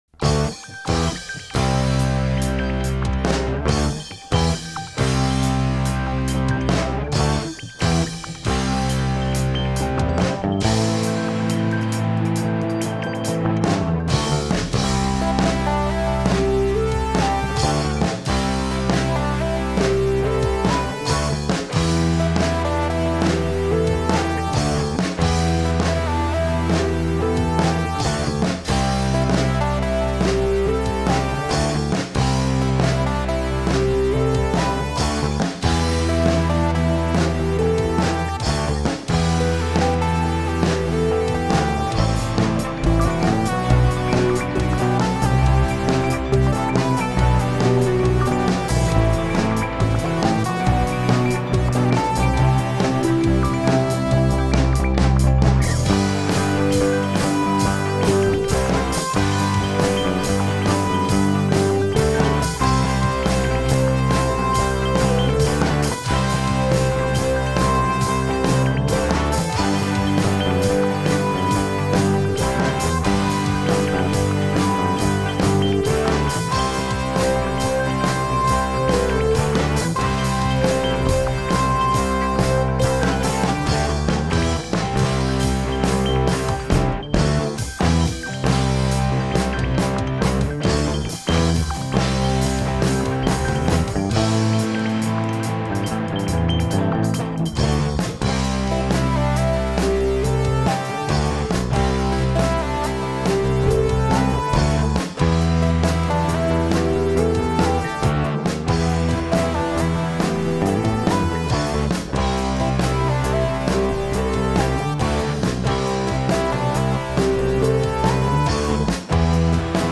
Pop Rock
pop-rock.mp3